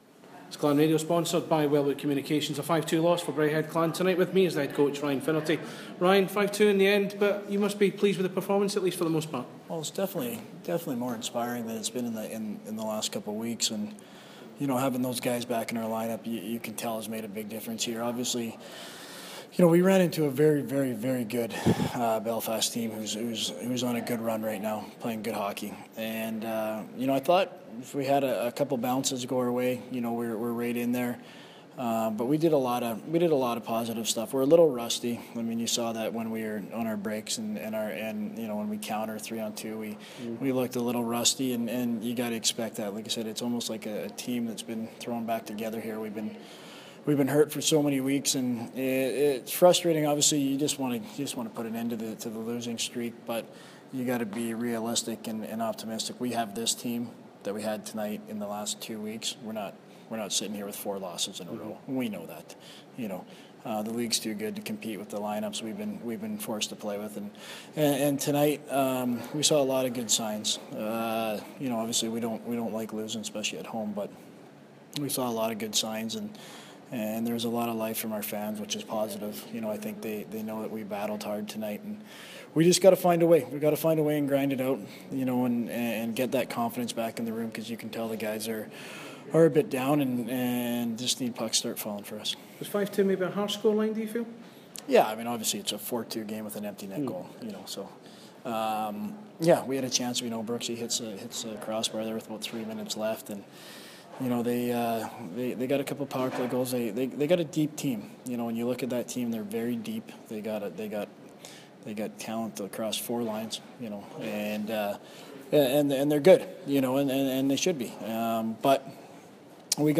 POST MATCH